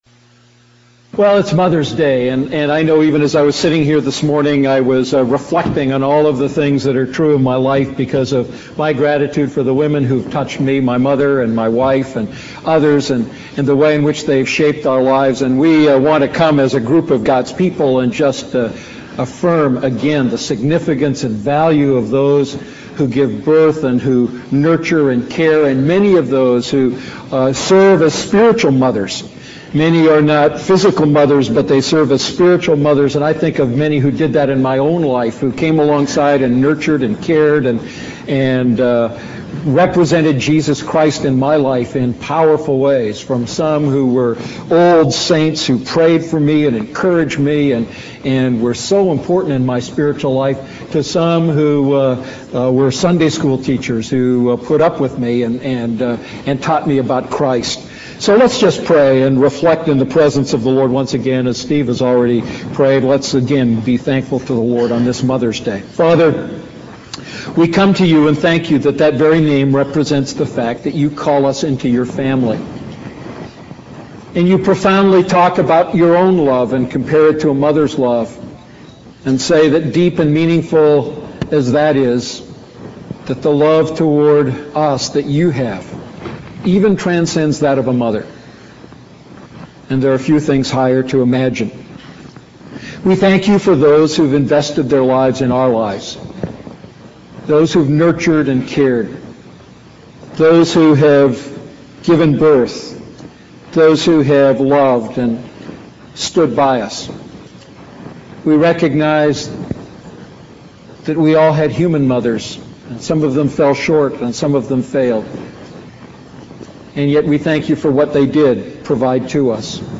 A message from the series "I John Series."